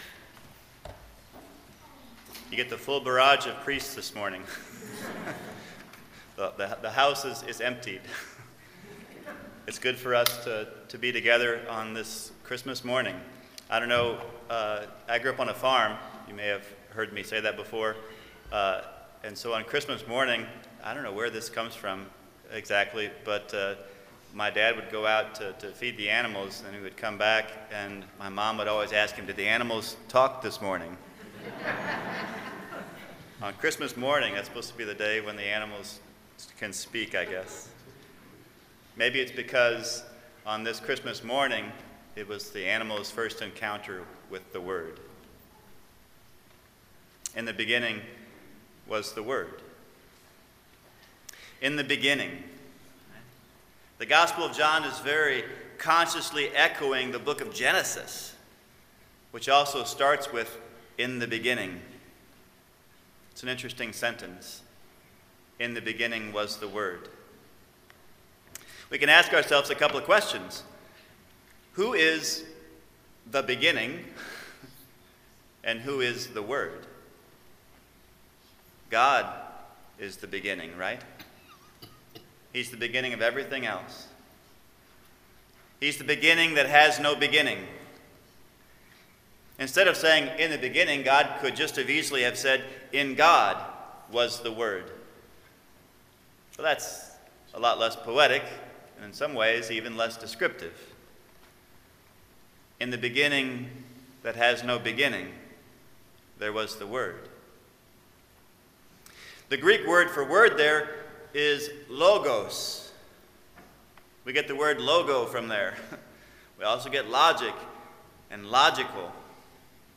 Christmas Day Mass 10:00 am: Serve God wittily in the tangle of your mind.